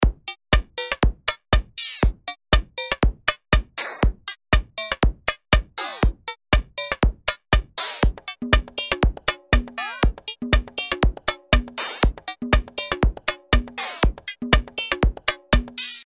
Und hier habe ich zusätzlich den Parameter Drop bewegt, der eine aufwärts oder abwärts gerichtete Frequenzmodulation des Klick-Geräuschs hinzufügt: